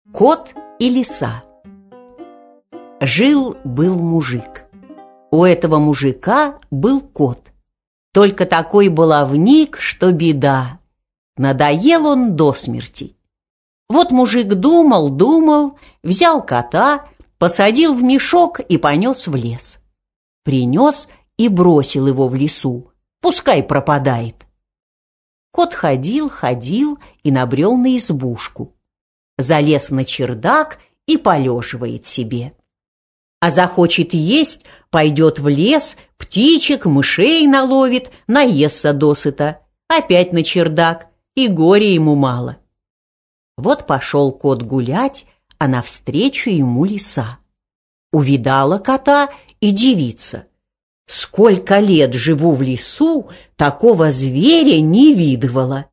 Аудиокнига Кот и Лиса | Библиотека аудиокниг